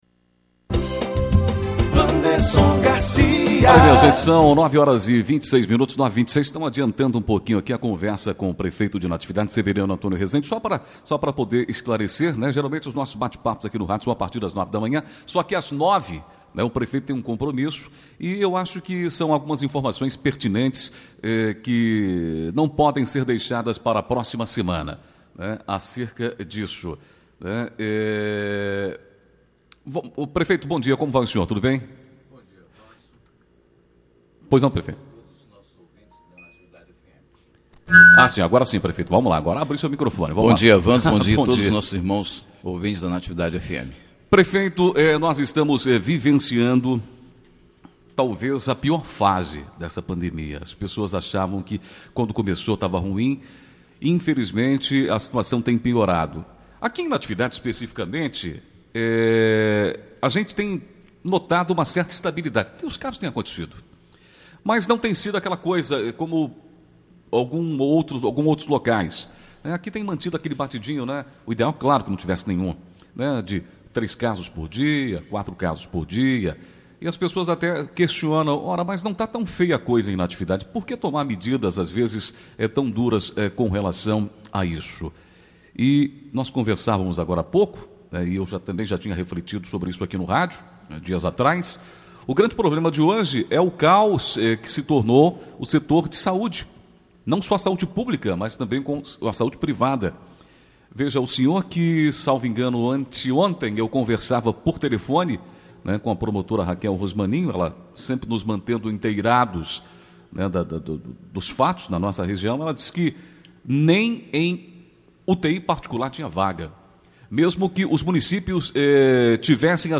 ENTREVISTA: Prefeito de Natividade não aderiu ao “superferiado” e vai defender a reabertura das igrejas – OUÇA – Natividade FM On-Line
Em entrevista concedida à Rádio Natividade na manhã desta sexta-feira (26), o prefeito Severiano Rezende falou sobre as medidas que estão sendo adotadas em conjunto com o Ministério Público que visam diminuir o contágio pelo novo coronavírus.
ENTREVISTA-SEVERIANO-02.mp3